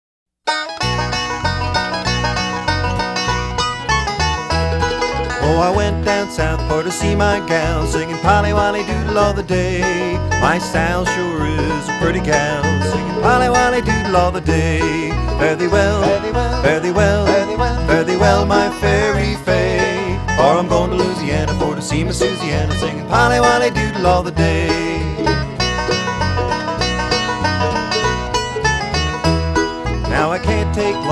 This collection of folk song favorites